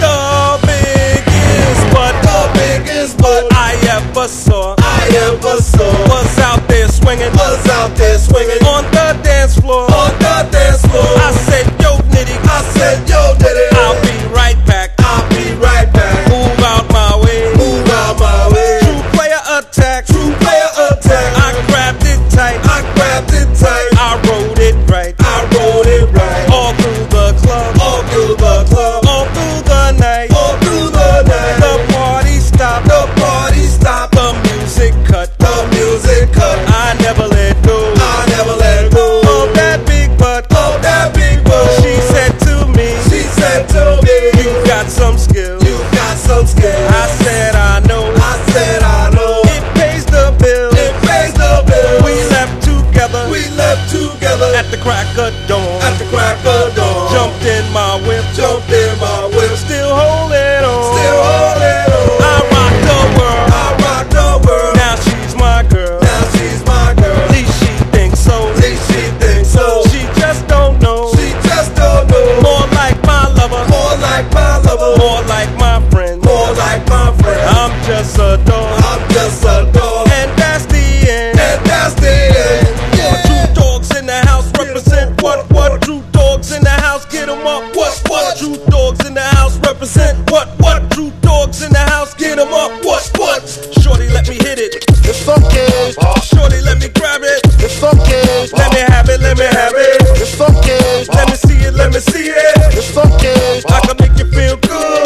HIP HOP/R&B / BREAKBEATS / MEGA-MIX / OLD SCHOOL / DISCO RAP
プレスミスがありますが当店のプレイヤーでは針飛びなく再生できました/